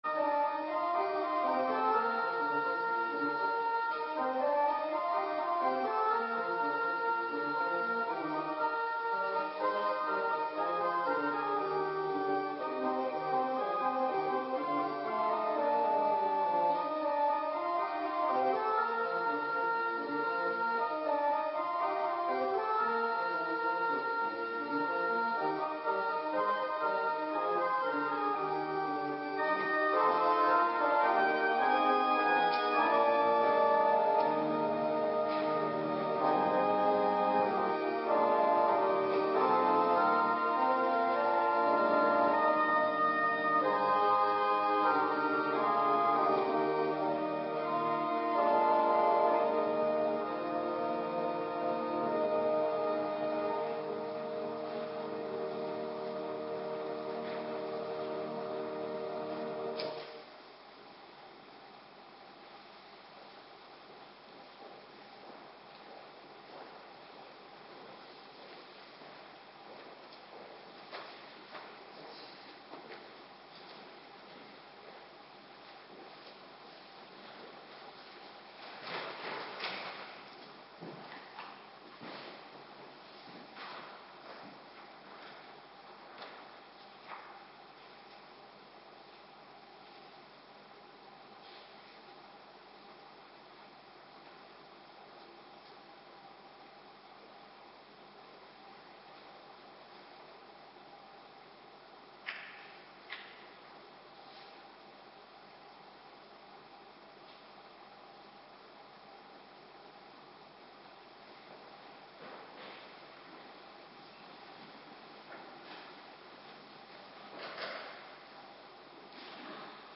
Locatie: Hervormde Gemeente Waarder